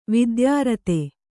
♪ vidyārate